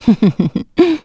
Hilda-Vox-Laugh_b.wav